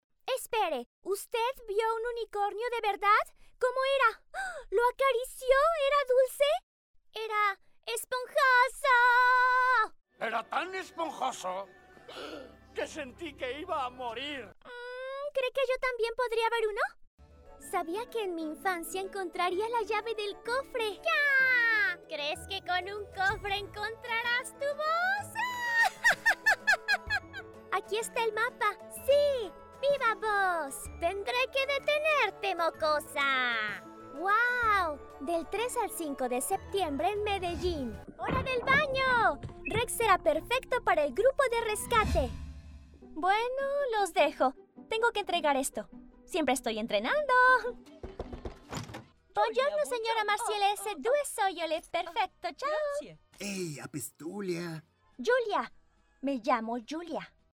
Spanisch (Lateinamerikanisch)
Junge, Natürlich, Freundlich, Sanft, Corporate
Persönlichkeiten
Her accent is known to be “neutral” or “international”.